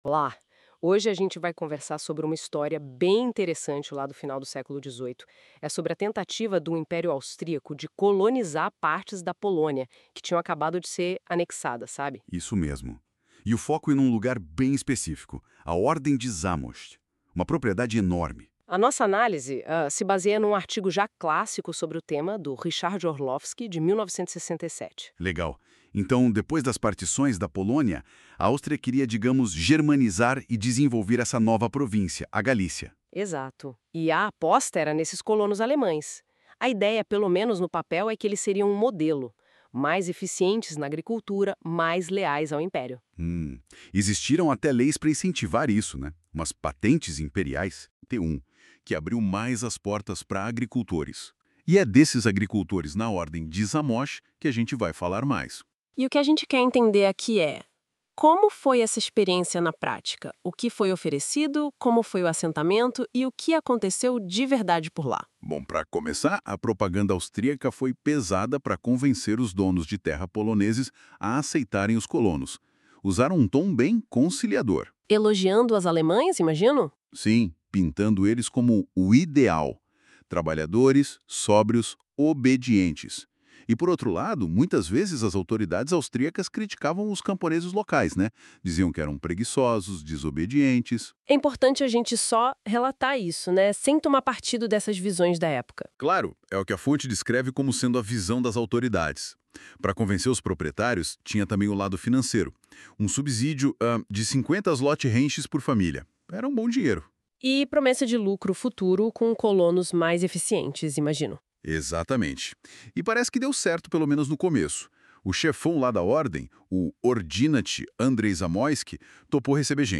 Resumo criado com NotebookLM Pro (Google) on 04.06.2025
• Resumo em áudio (podcast) em português.